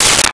Space Gun 05
Space Gun 05.wav